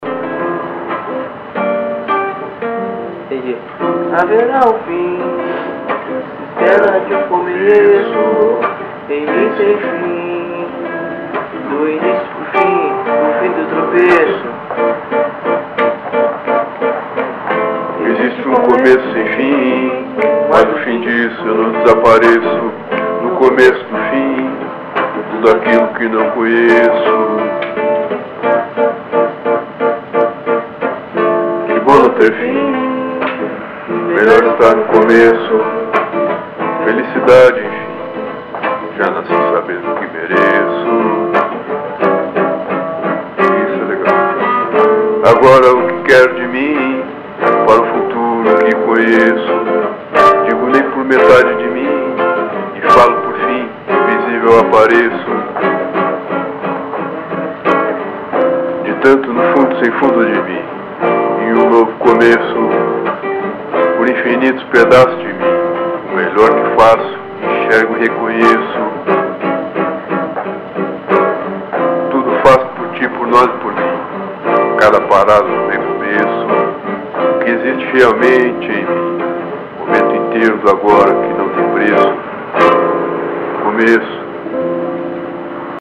Musicas gravadas no momento de criação, realizadas no improviso, sem ensaio, sem estúdio ( faltam mais instrumentos em arranjos e ensaio, outras Letras melhores e mais importantes estão para terem acompanhamento de violão e ritmo, ou menos, ou mais, para virarem Novos Protótipos ou Esboços como base para Gravação )...